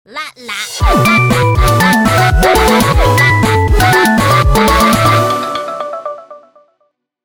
• The team jingle!